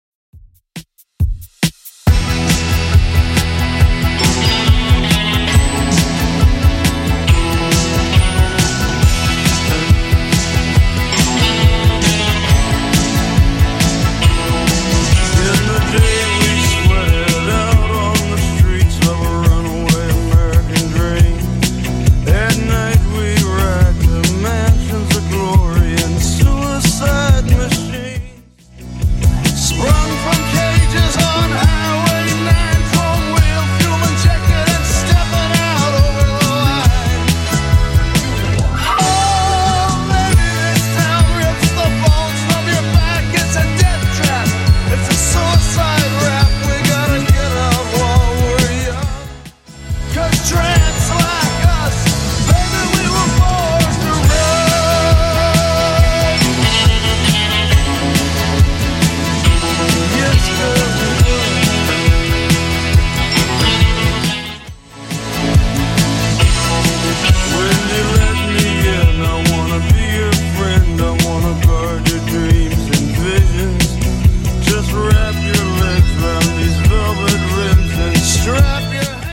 BPM: 138 Time